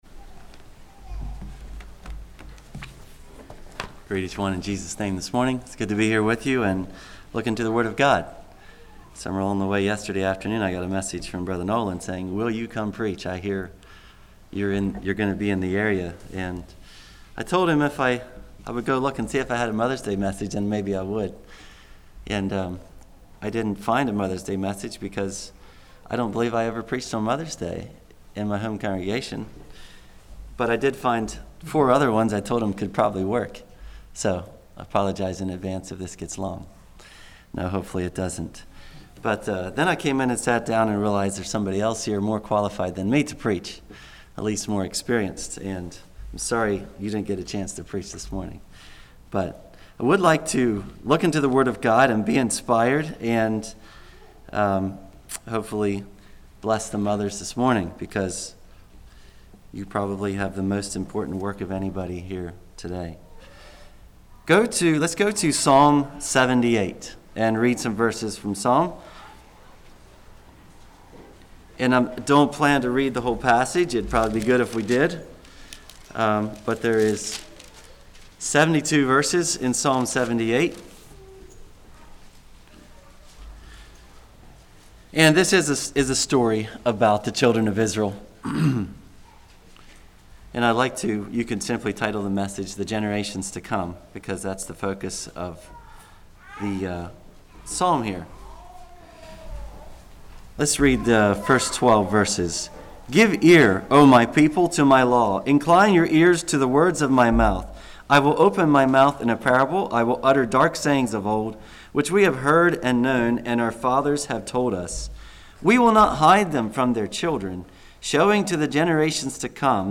37:03 Summary: Mothers Day message on how to raise the next generation. 1.